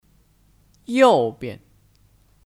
右边 Yòubian : Sebelah kananAlternatif: 右面 Yòumian (Jarang digunakan)